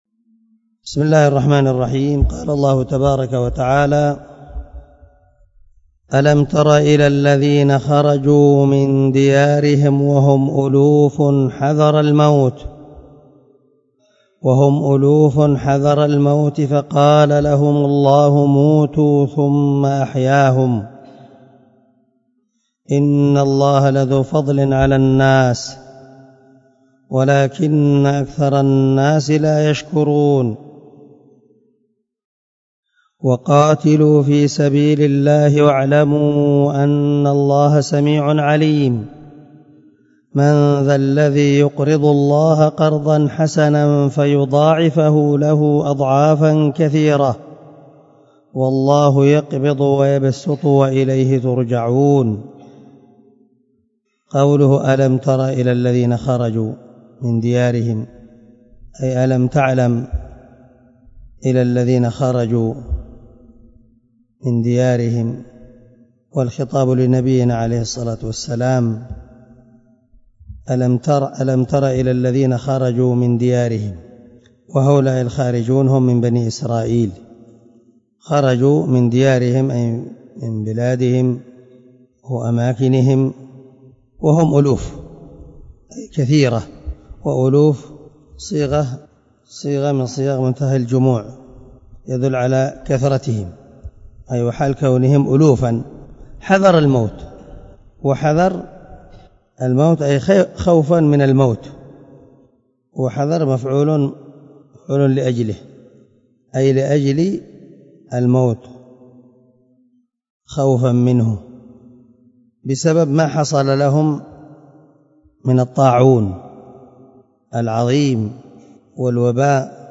127الدرس 117 تفسير آية ( 243 - 245 ) من سورة البقرة من تفسير القران الكريم مع قراءة لتفسير السعدي